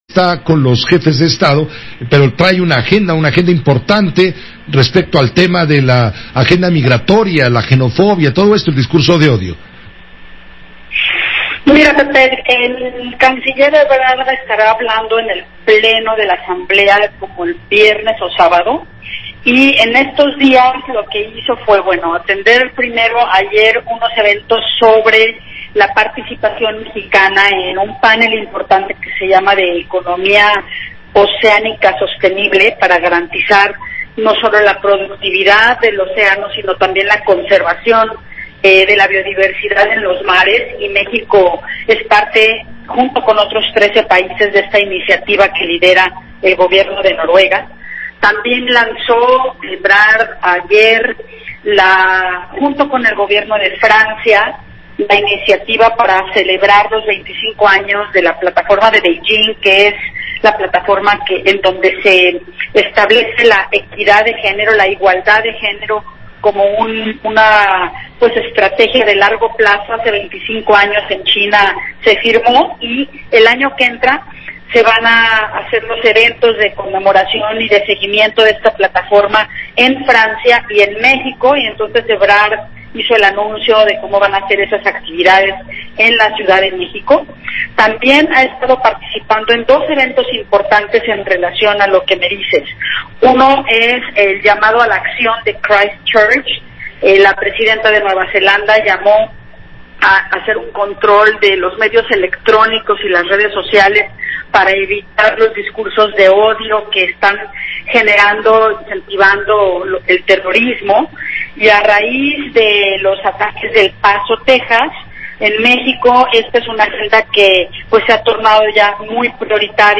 [AUDIO] Entrevista en Radiofórmula con José Cárdenas sobre la 74 AGONU